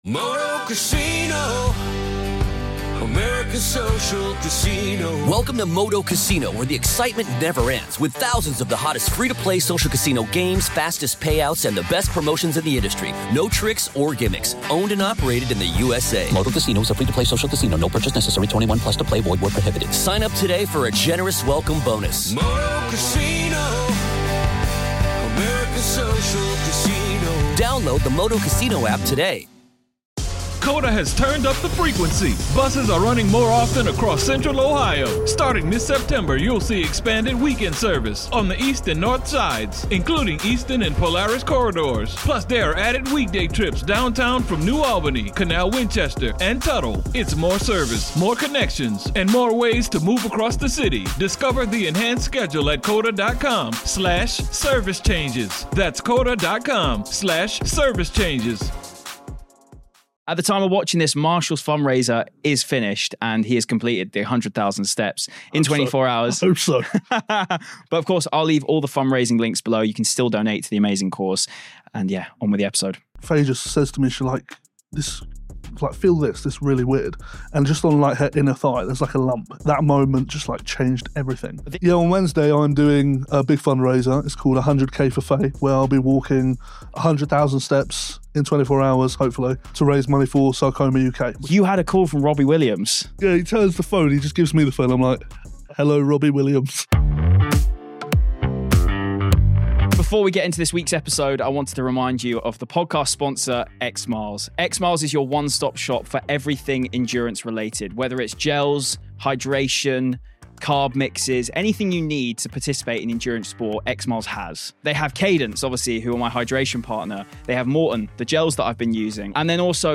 Really enjoyed this chat, such an amazing bloke.